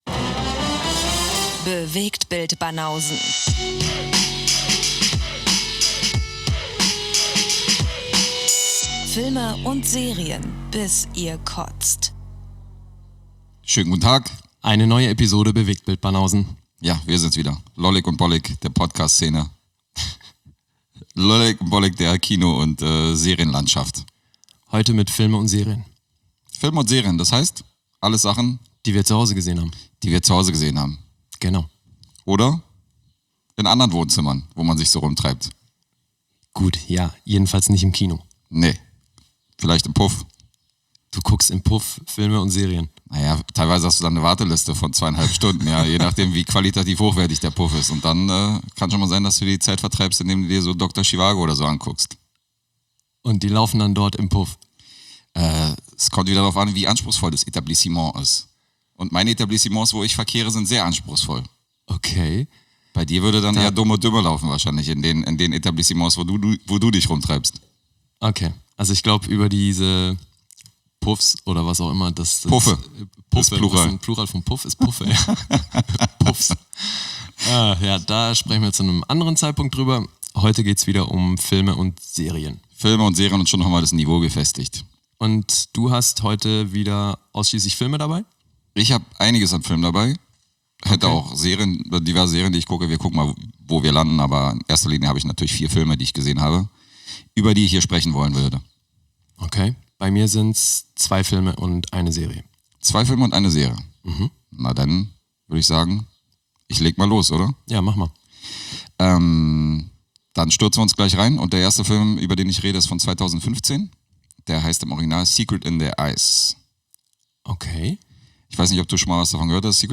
Zwei Dudes - manchmal mit Gästen - quatschen über Gesehenes aus Kino, Homekino und Streaming-Plattformen und punkten zudem mit gefährlichem Halbwissen.